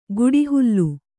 ♪ guḍihullu